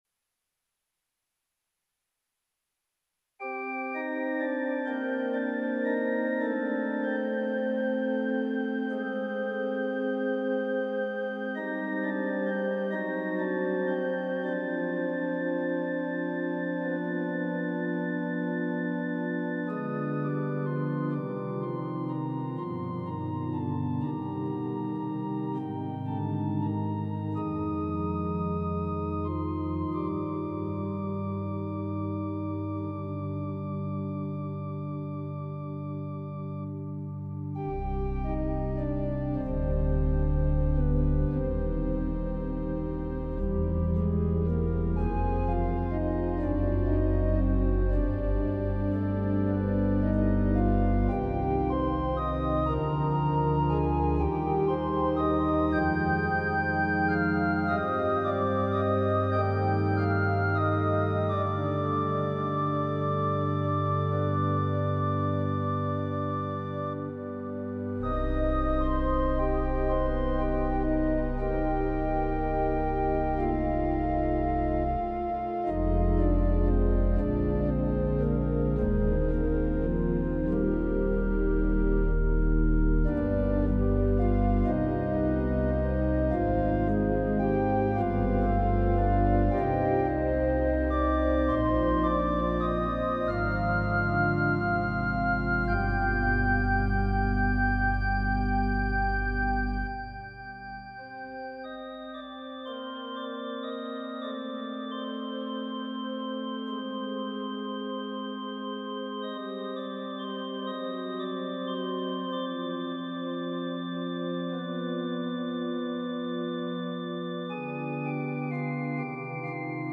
Peaceful works for quiet reflection